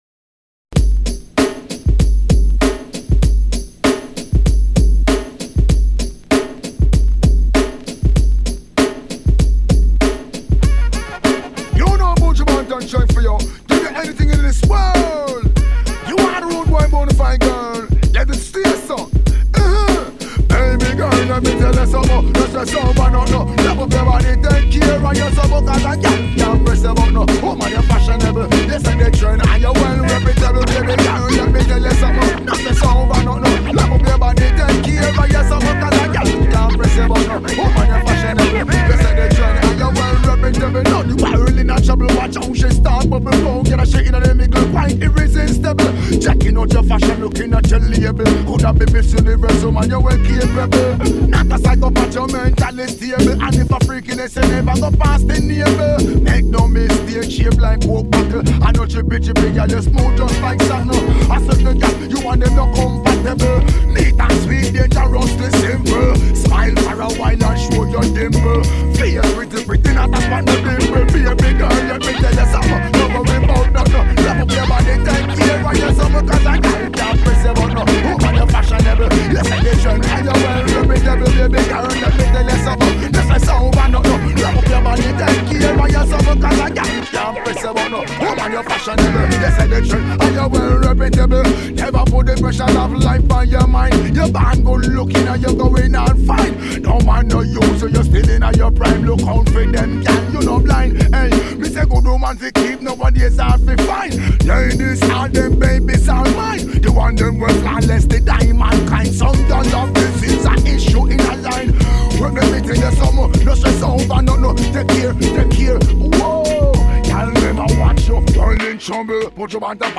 90s HipHopクラシック